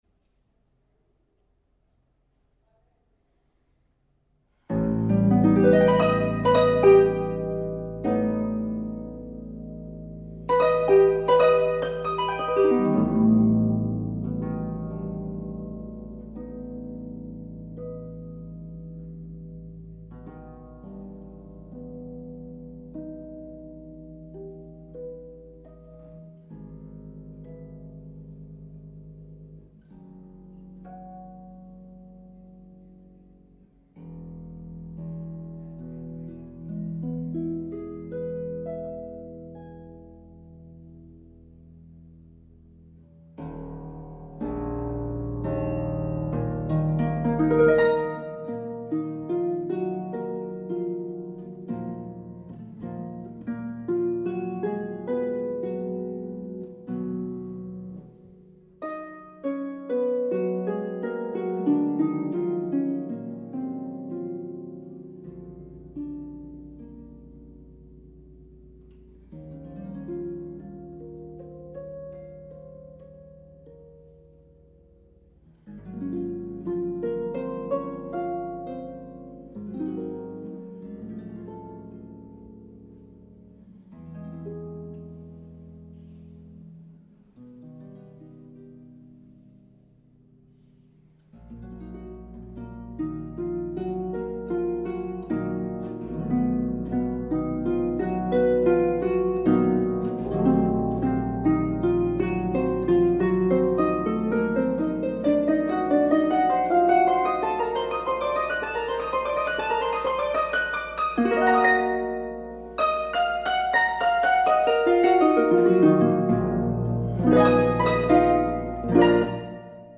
Live at Thayer Hall June 2021